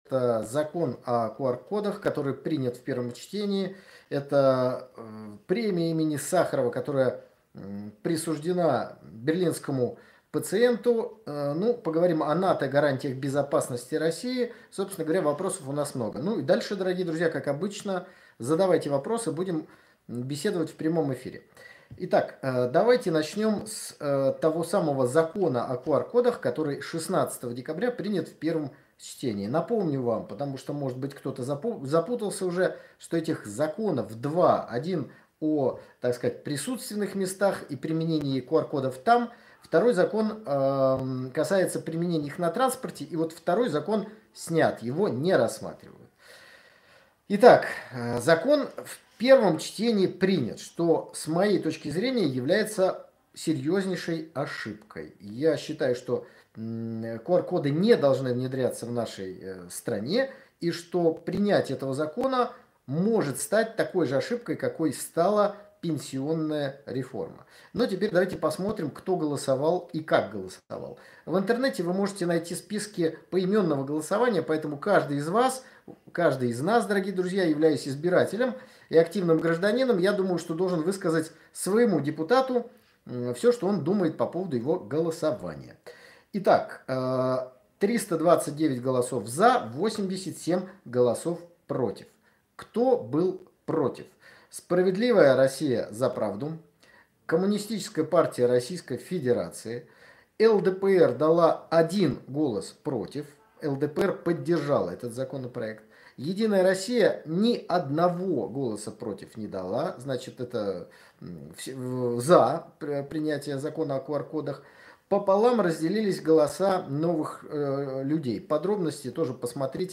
В очередном еженедельном эфире поговорили про закон о QR-кодах и предложении ввести штрафы за отказ от вакцинации, обсудили вручение премии им.